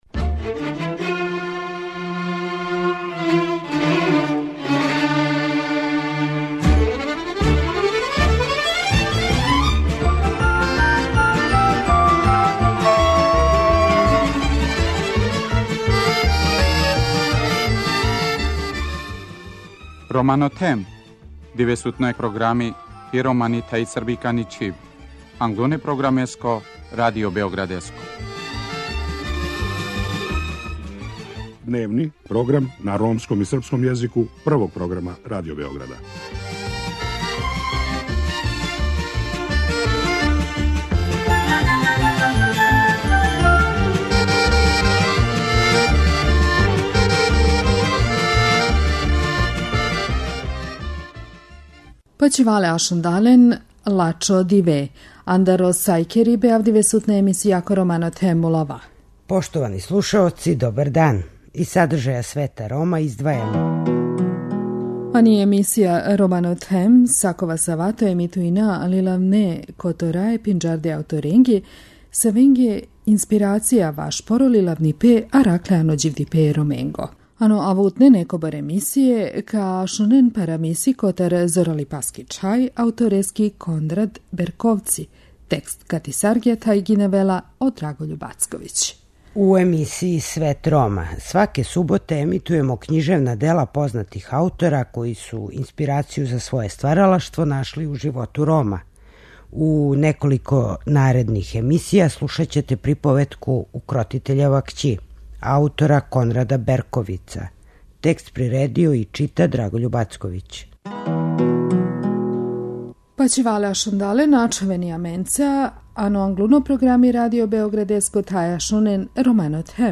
У емисији Свет Рома, сваке суботе емитујемо књижевна дела разних аутора којима су Роми били инспирација. У наредних неколико издања слушаћете приповетку Укротитељева кћи аутора Кондрада Берковица.